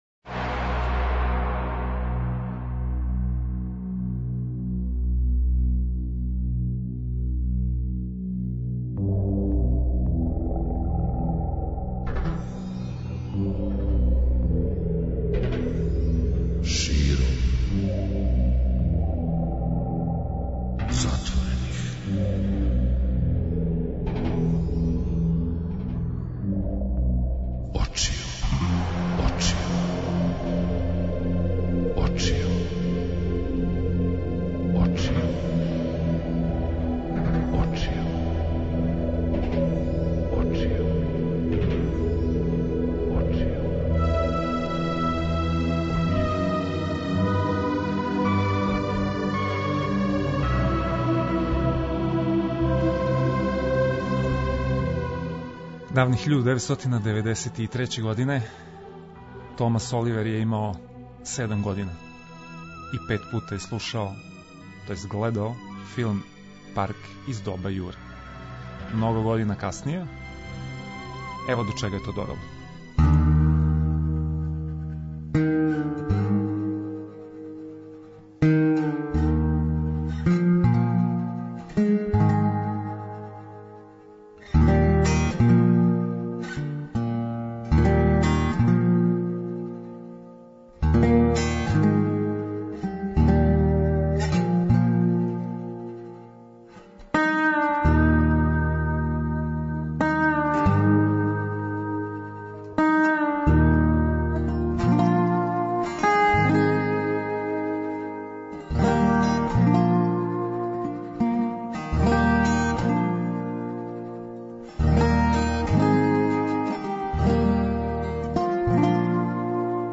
Емисија која се бави научном фантастиком, хорором и фaнтазијoм - у књижевности, филму, музици, стрипу, компјутерским играма, као и у свим осталим њиховим појавним облицима.